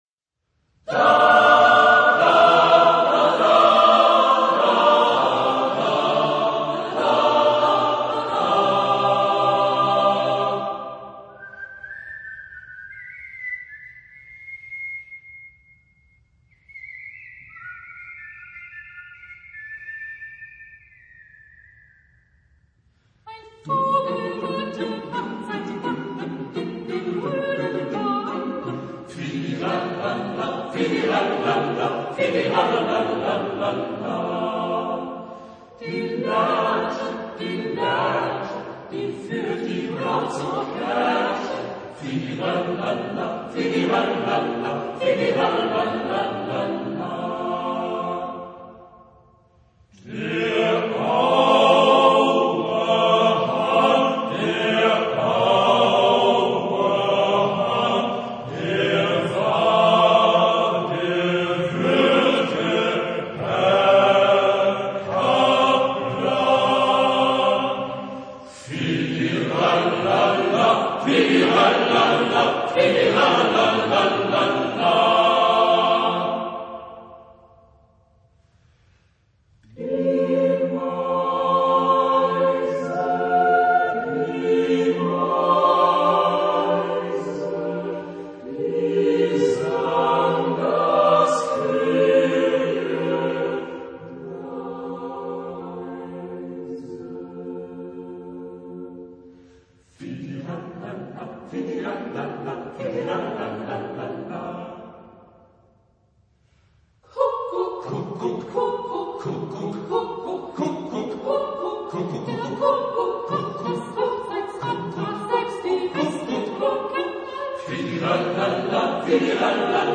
Chorgattung: SATB  (4 gemischter Chor Stimmen )
Solisten: Sopran (1)  (1 Solist(en))
Tonart(en): F-Dur